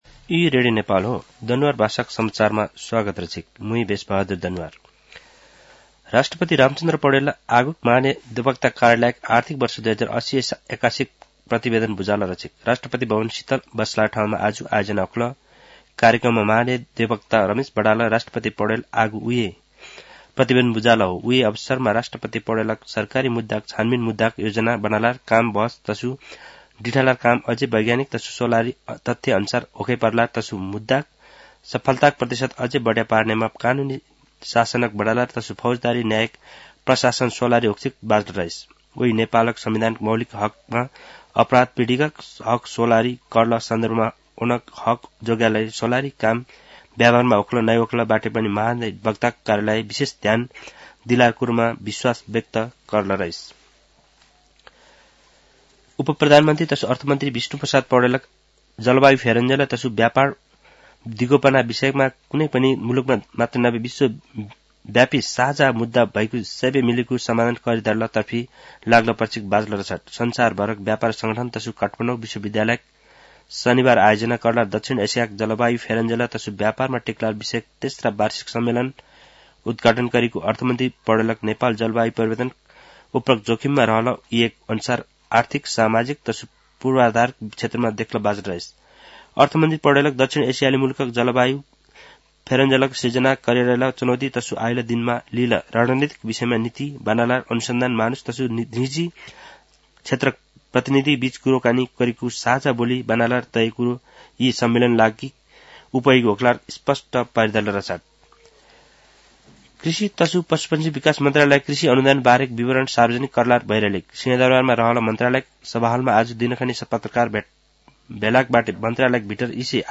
दनुवार भाषामा समाचार : २९ पुष , २०८१
Danuwar-news-4.mp3